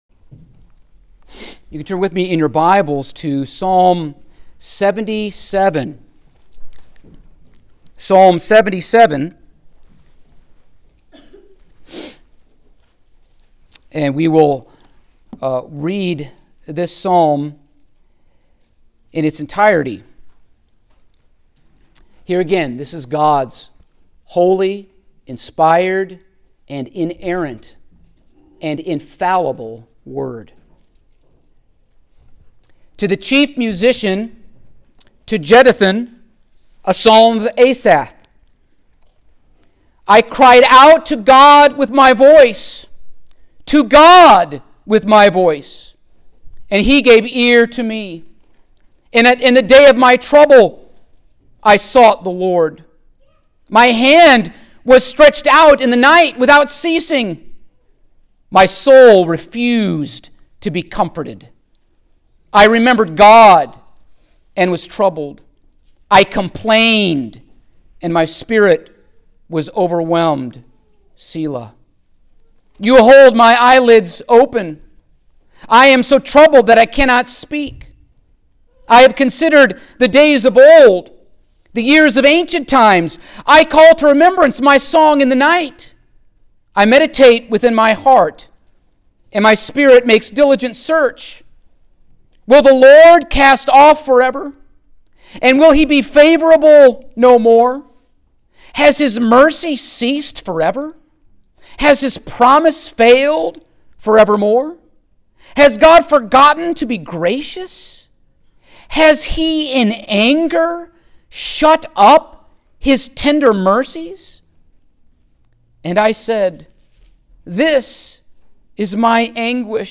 Psalm 77 Service Type: Sunday Morning Topics